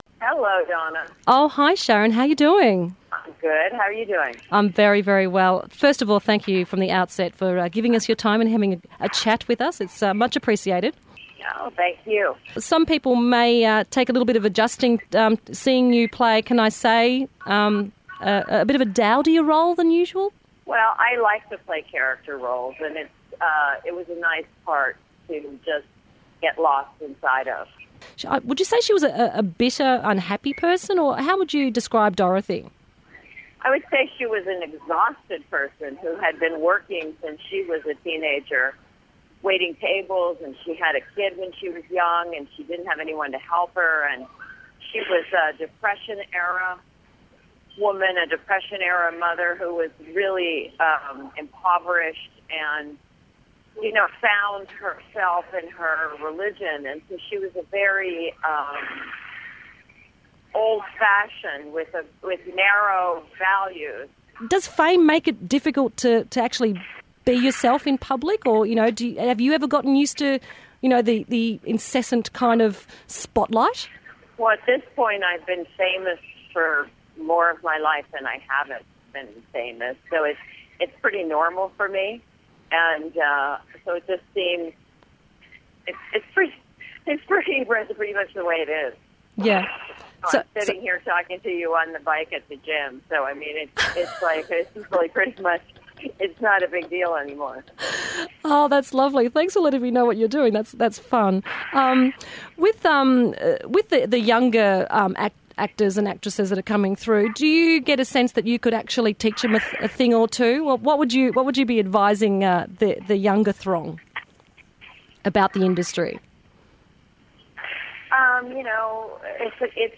I snared an Australian radio exclusive with the Basic Instinct star